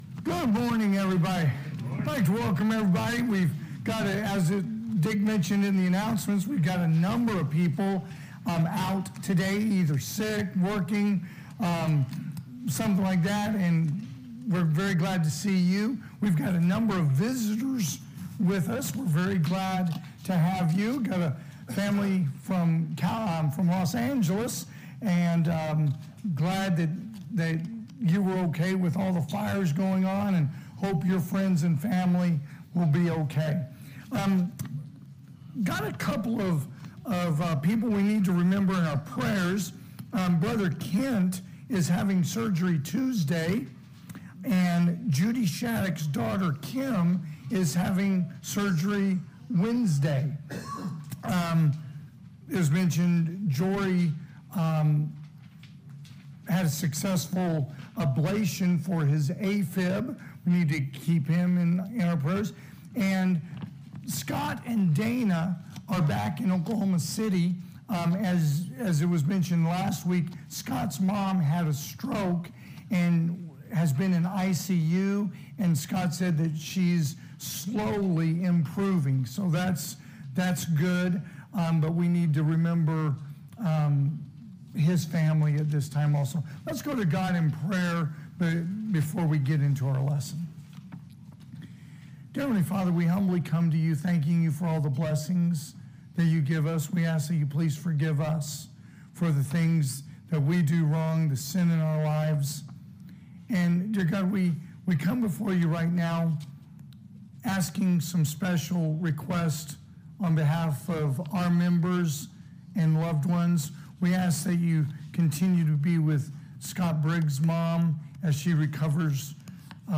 2025 (AM Worship) “Singing?